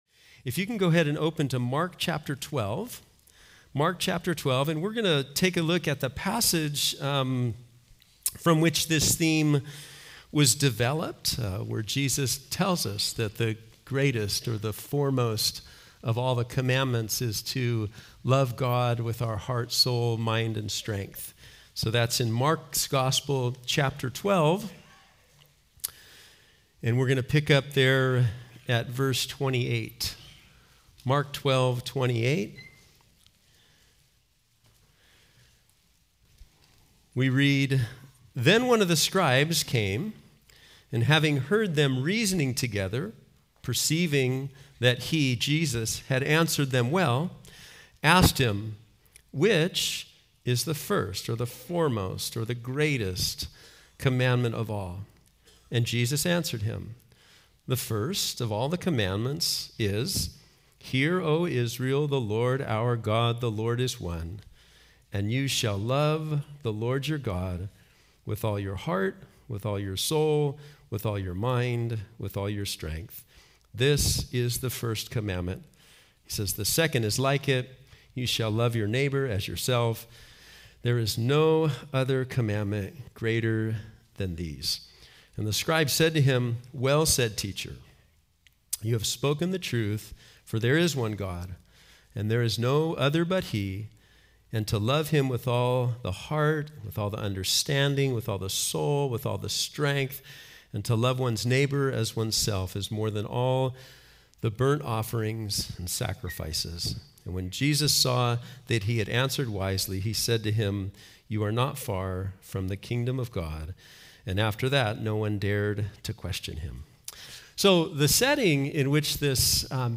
Home » Sermons » Loving God with All Your Heart